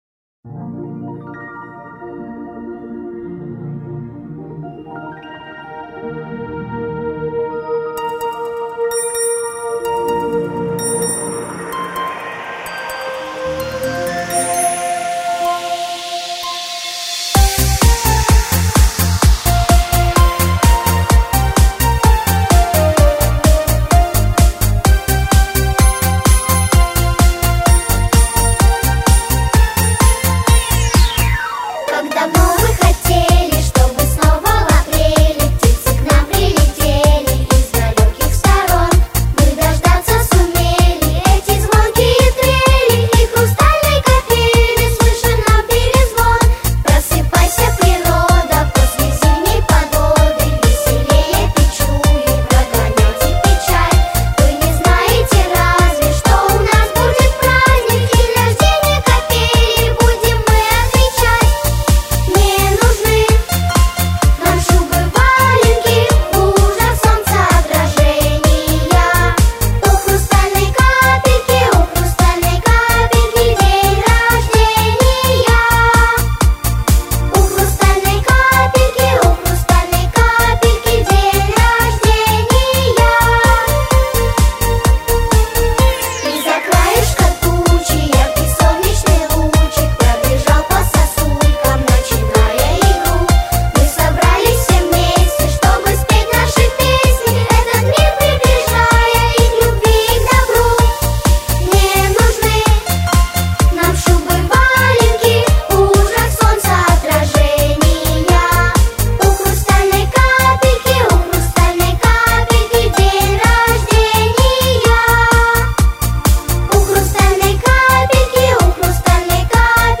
🎶 Детские песни / День рождения 🎂